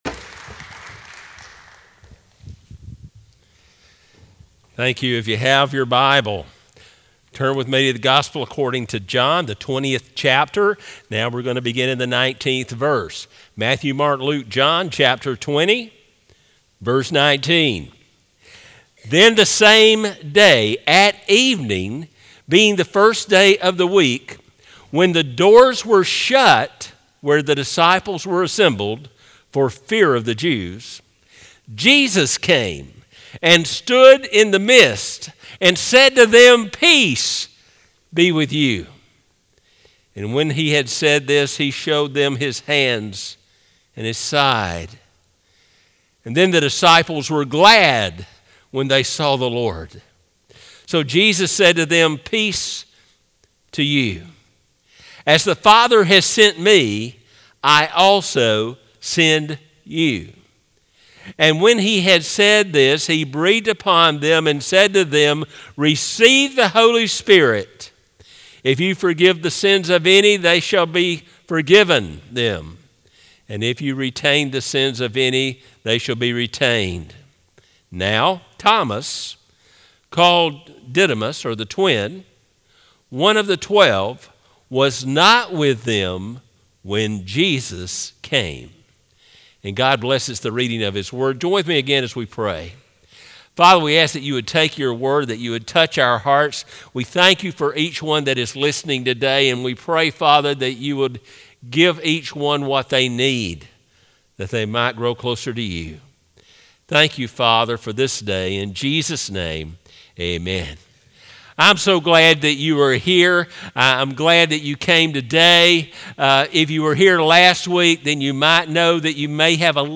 Sermons - Northside Baptist Church